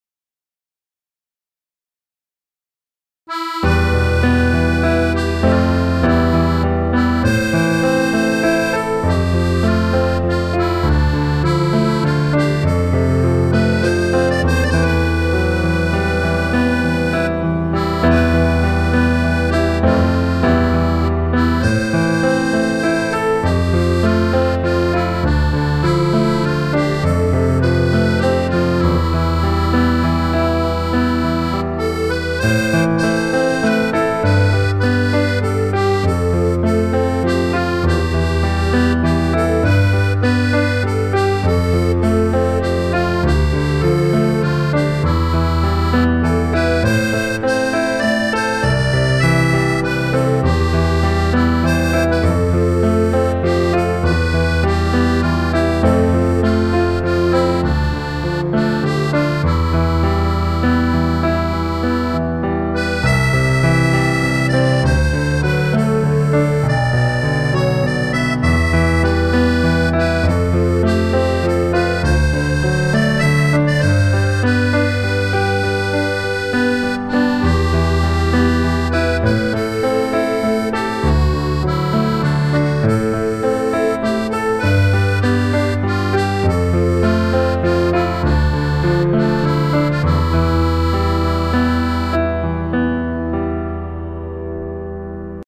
מקאם-כורד.mp3